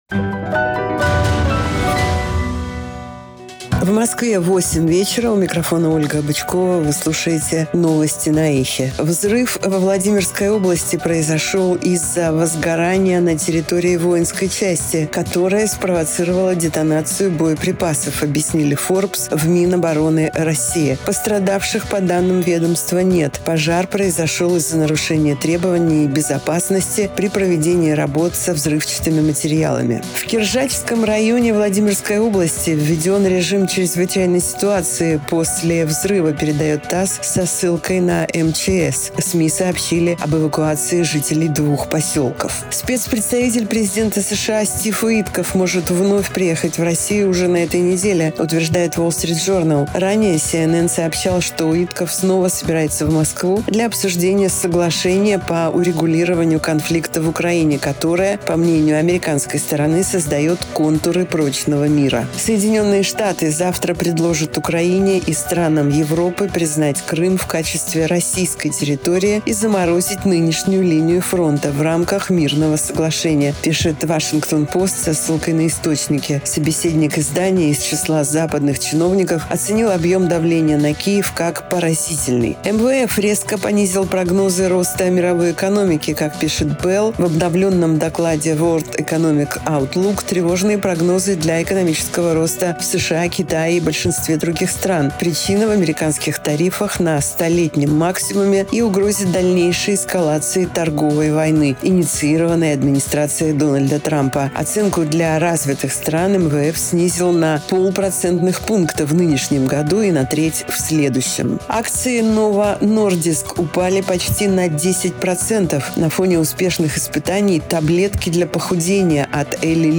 Слушайте свежий выпуск новостей «Эха»
Новости 20:00